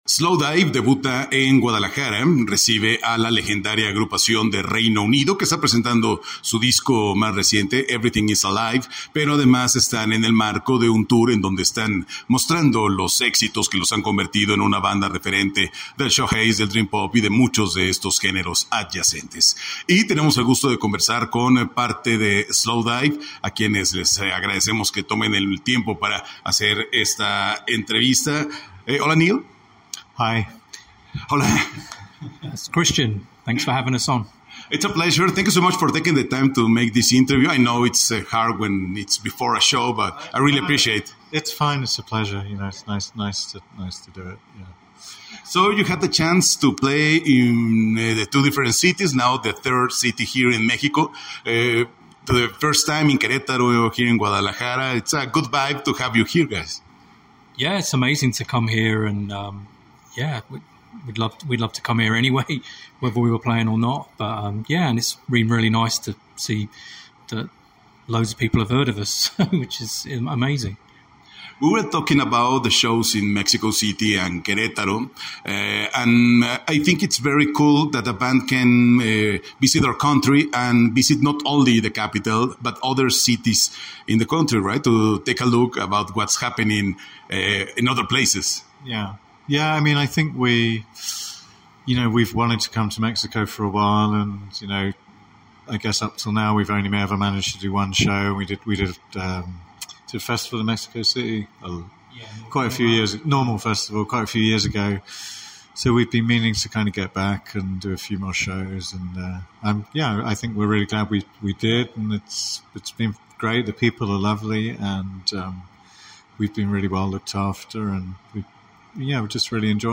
Escucha nuestra conversación con el grupo previo a su show.
Entrevista-Slowdive-show-2024.mp3